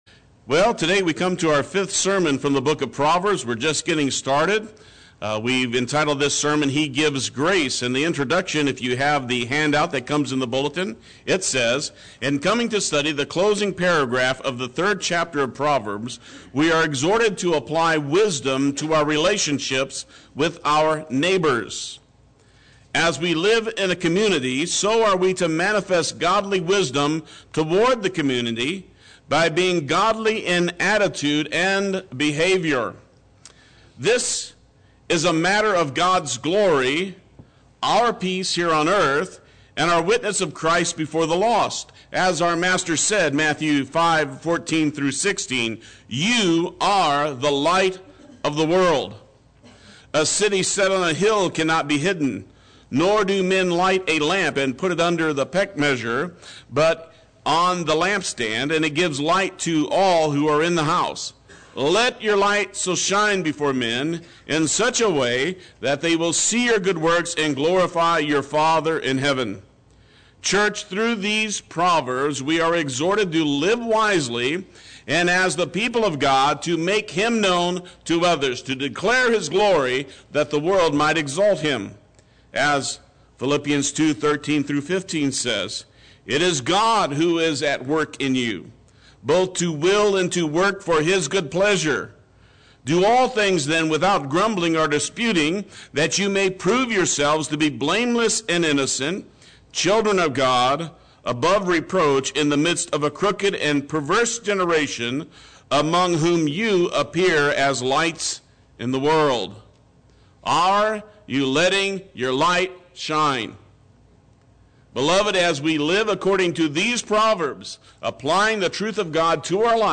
Play Sermon Get HCF Teaching Automatically.
He Gives Grace Sunday Worship